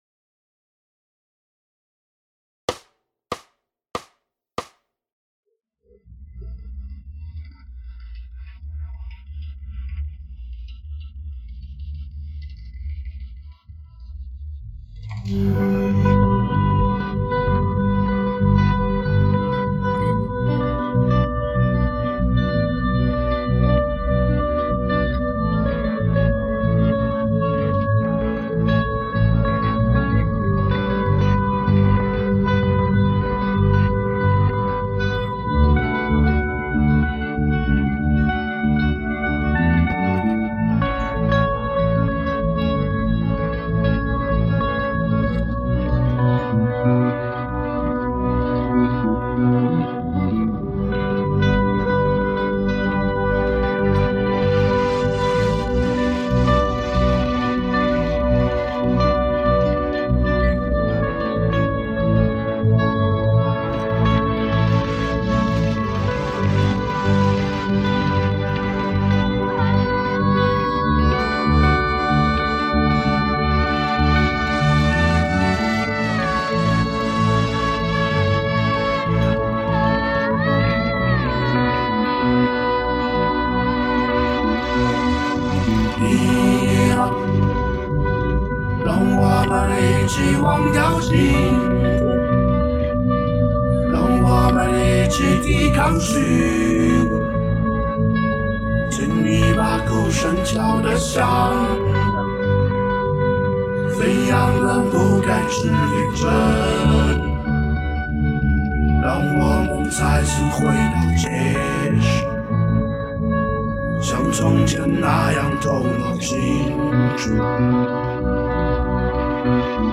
乐队/组合